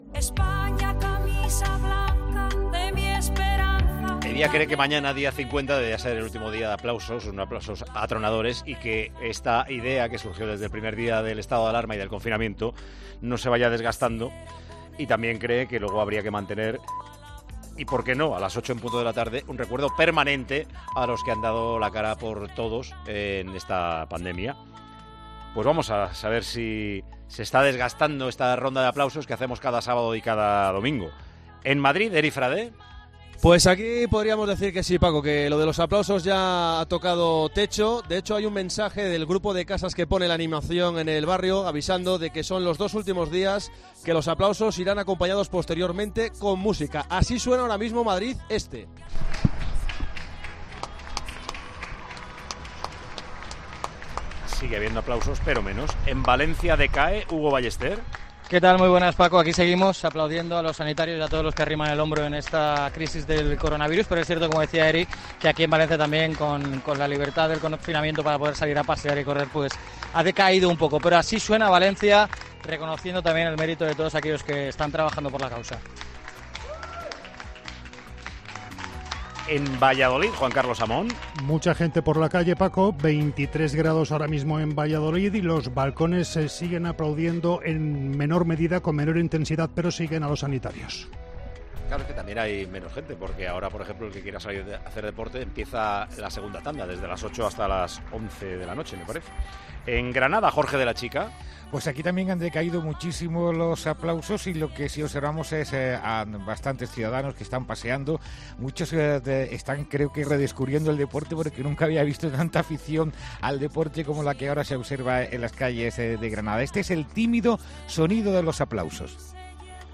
Ronda de aplausos del sábado 02 de mayo de 2020
Tiempo de Juego Ronda de aplausos del sábado 02 de mayo de 2020 La fase inicial del desconfinamiento, la apertura de la calle con los runners y también el paso del tiempo nos deja un sonido de aplausos más débil de lo habitual.